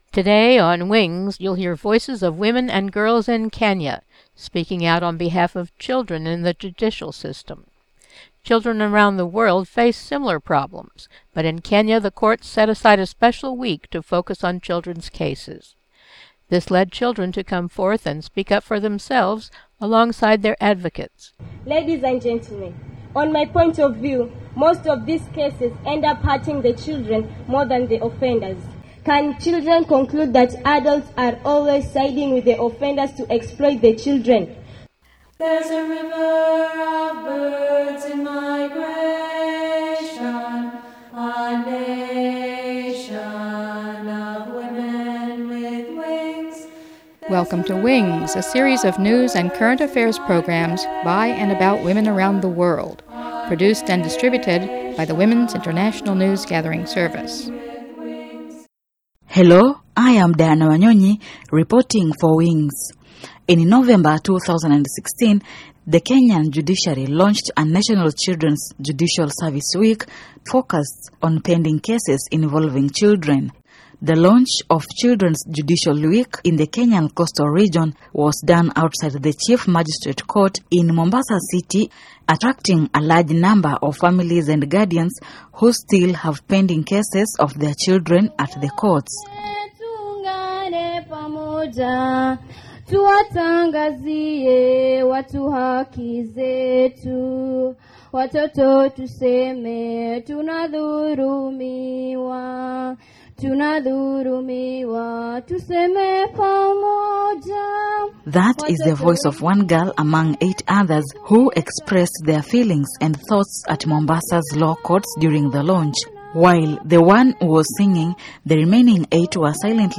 Children and advocates speak at National Children's Judicial Service Week